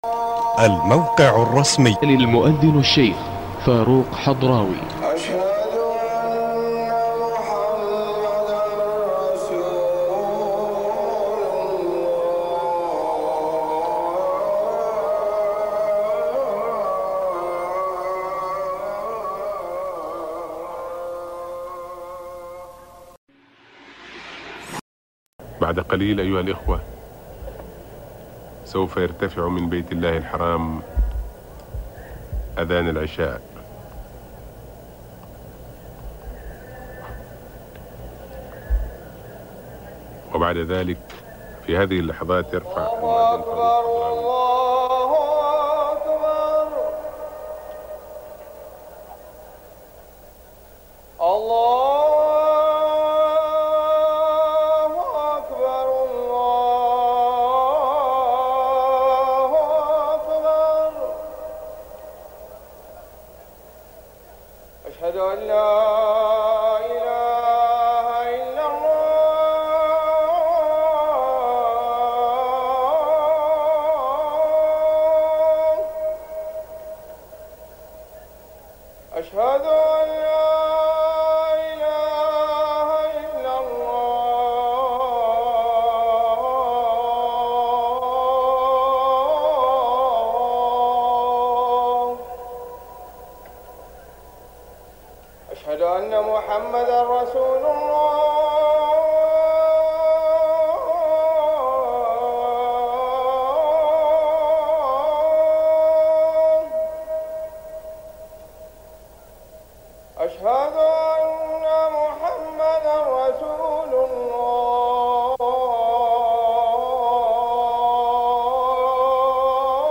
نوادر الأذان 🕋